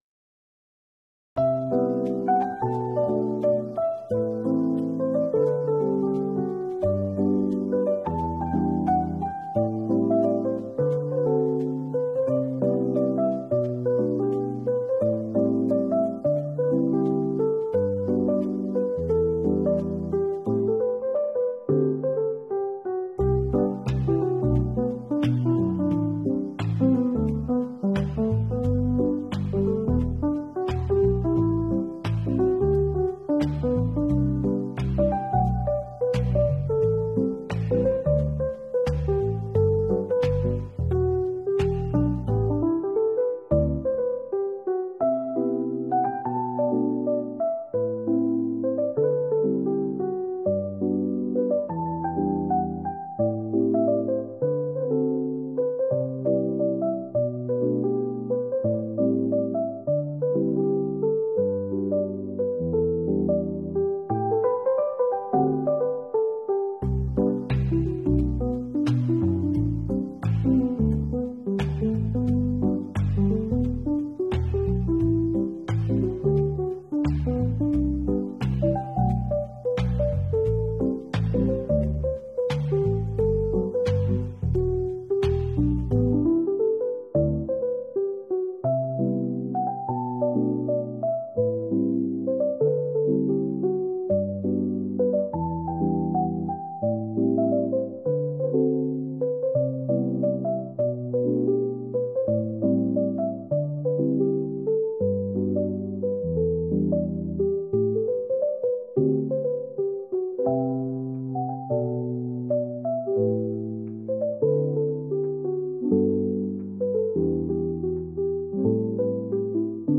Chill Jazz Fusion BGM